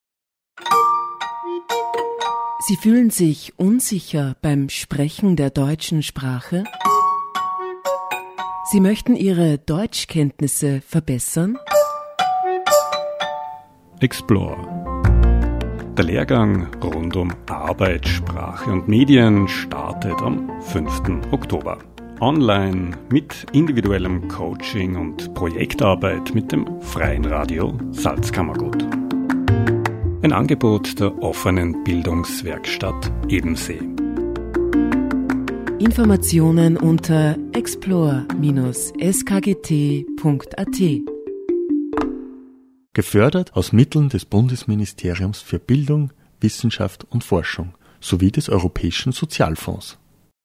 Radio Jingle – EXPLORE LEHRGANG 2020 BILDUNG